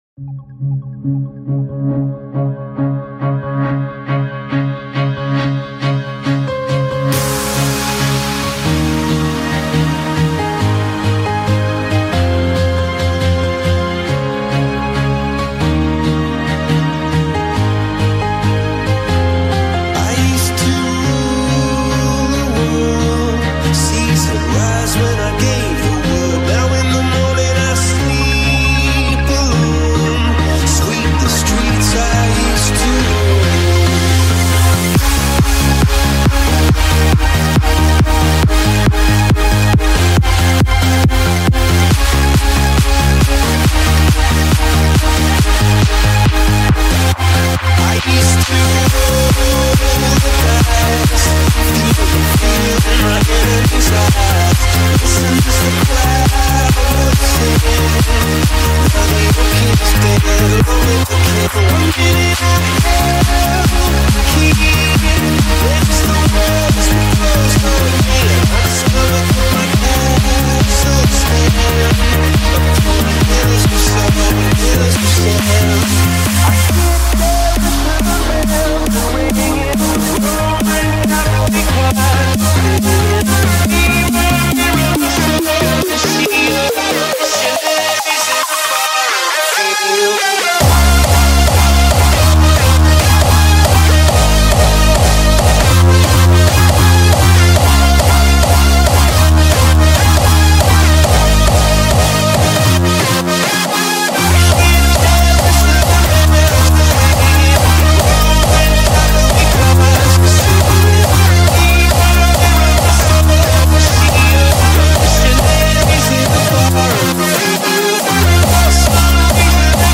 Hardstyle Remake